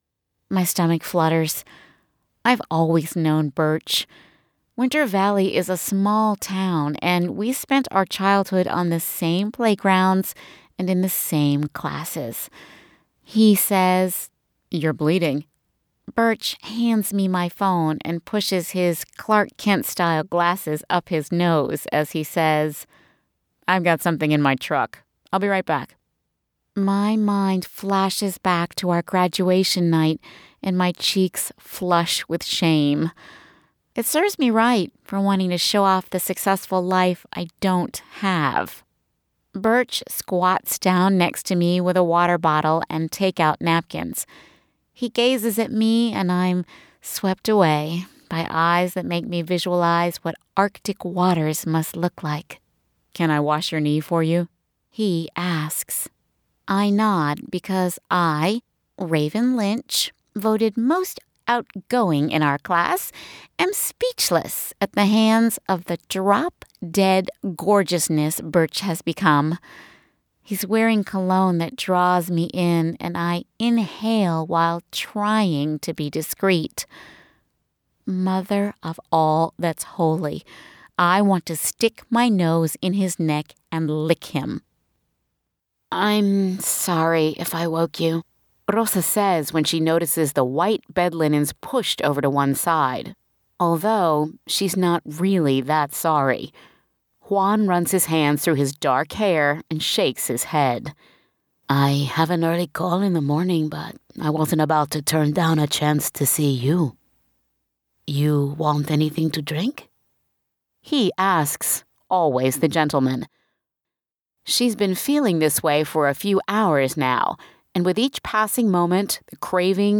ROMANCE AUDIOBOOKS 6 min